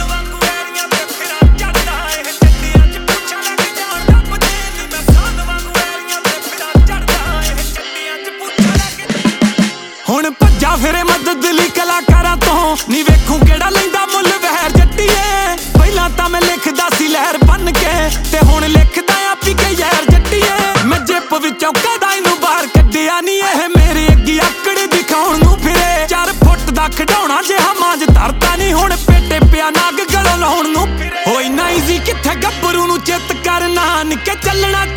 Жанр: Иностранный рэп и хип-хоп / Поп / Рэп и хип-хоп